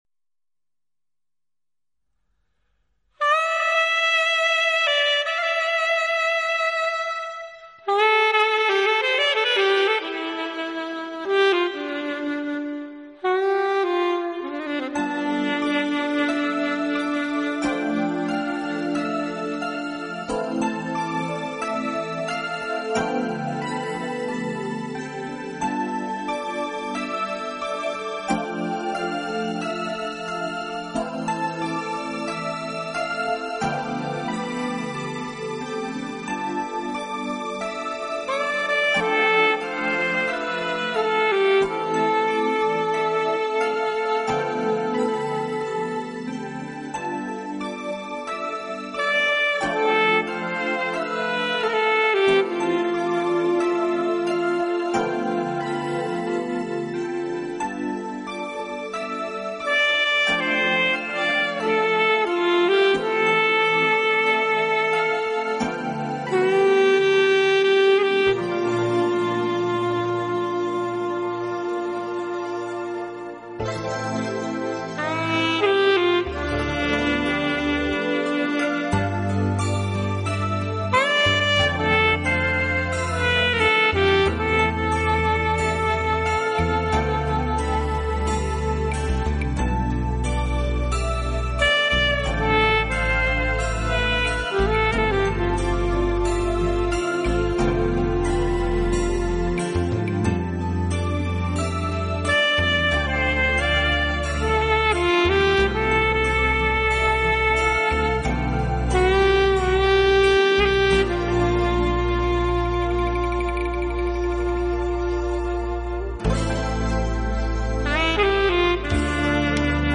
【情感萨克斯】
与感性的萨克斯。
没想到，悠悠的萨克斯也可有如此豪气，灵秀。